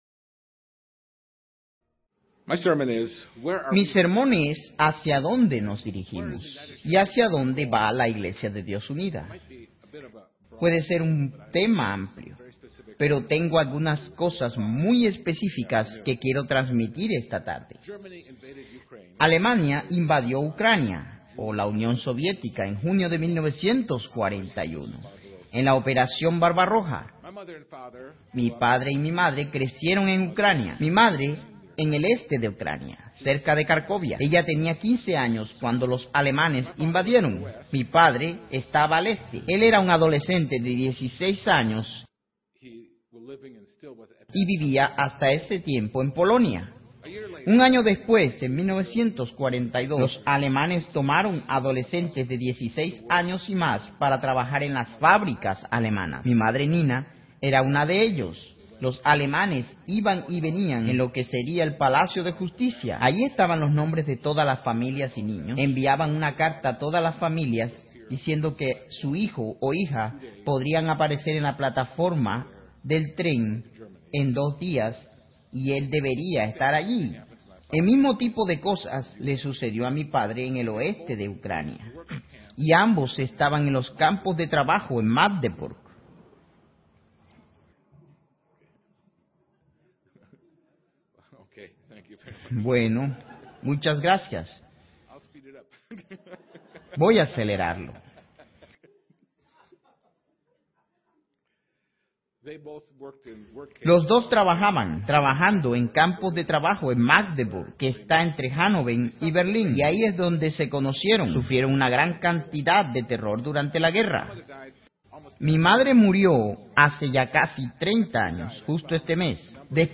Este sermón se pronunció en el lugar de Fiesta de Cincinnati, Ohio 2015.